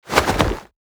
UI_Inventory_Open.ogg